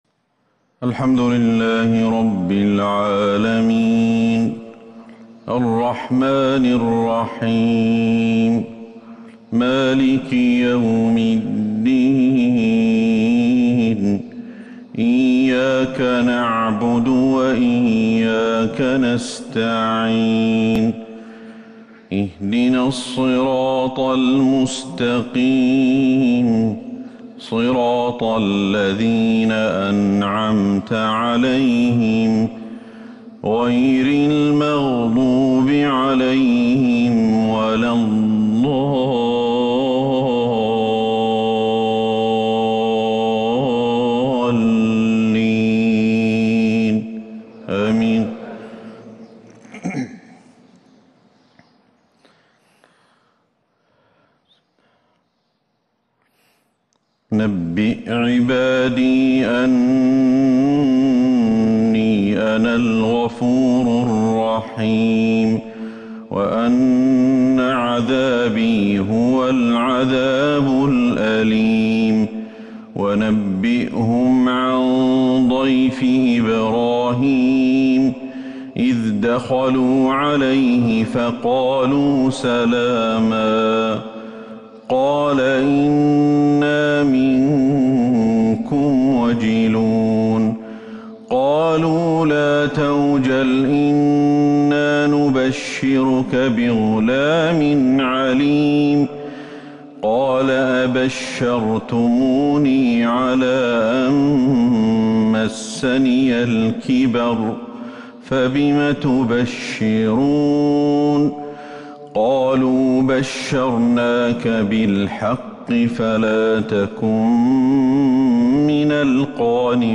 فجر السبت 3-6-1442هـ من سورة الحجر | Fajr prayer from Surah Al-Hijr 16/1/2021 > 1442 🕌 > الفروض - تلاوات الحرمين